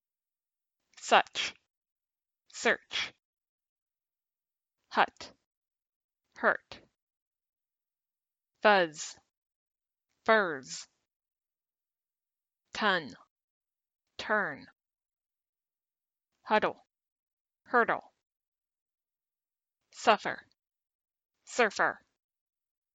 Home Pronunciation Checker Academy American English Sounds - /ʌ/ mid, central, lax vowel
However, /ʌ/ is pronounced with a central tongue, while /ɜr/ is pronounced with a slightly higher tongue.
You can hear the difference between /ʌ/ and /ɜr/ in these words.
compare_uh-er_words.mp3